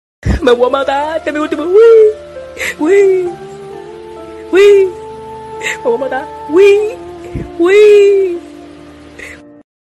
wee weee weee Meme sound effects free download